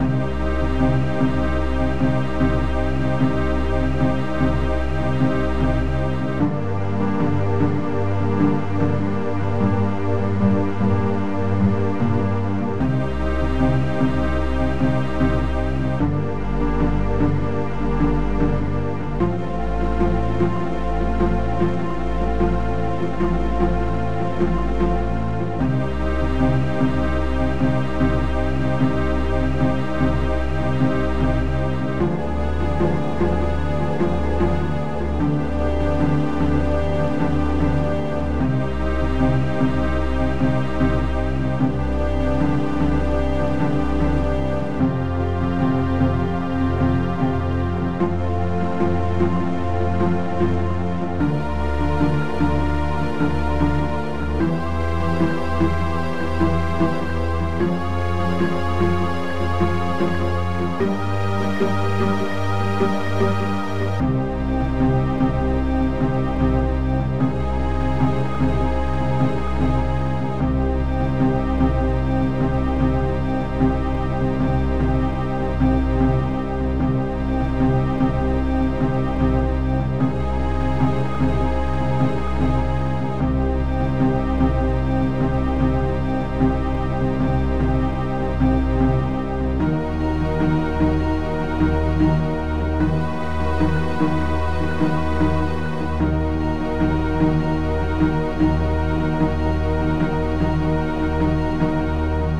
snaretom
orch hit hard c=a
trumpet high
strings ultra